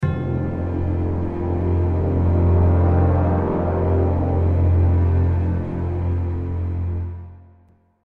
En rajoutant un son de cloches tubulaires, je vais obtenir une attaque plus limpide, et puis ça va apporter un petit côté à la fois mystérieux et solennel (très cinématographique).
Contrebasses + tuba + accord dissonant de trombones + timbales + cloches tubulaires :
Cloches Tubulaires
attaque_grave_cloche.mp3